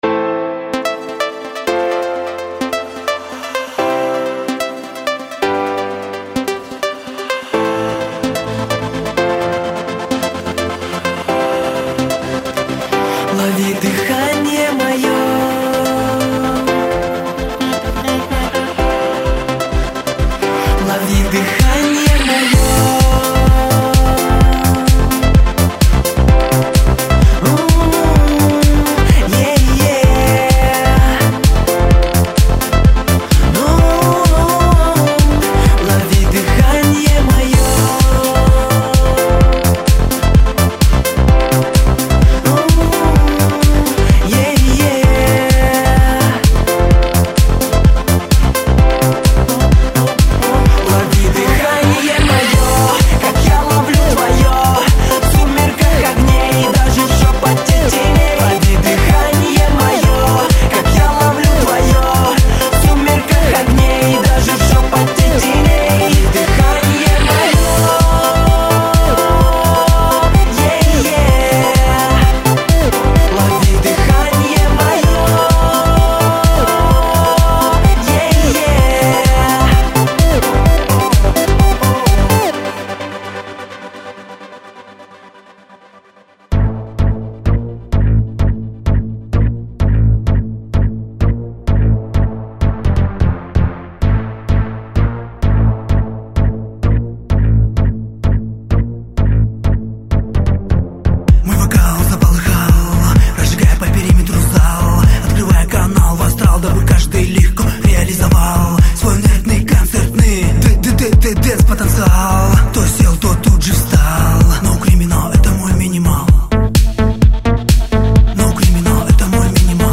Категория: Dance